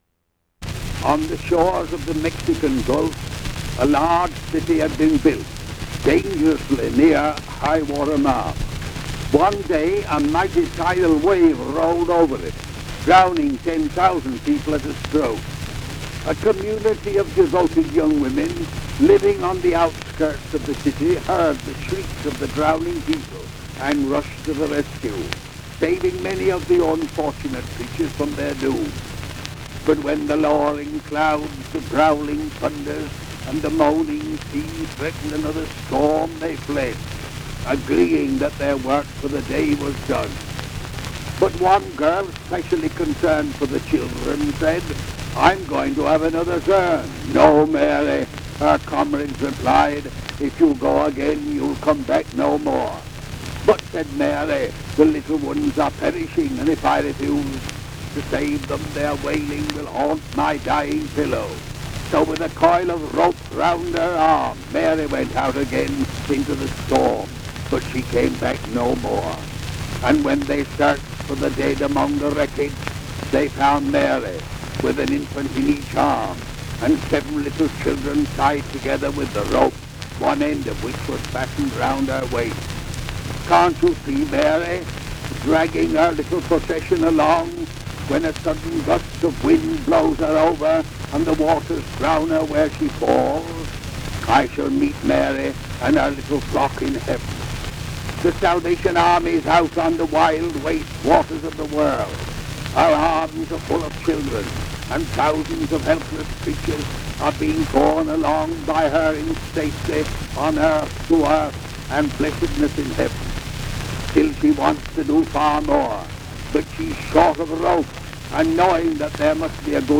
An address by the late General William Booth, founder of the Salvation Army.
Recorded by Victor Talking Machine, 1910.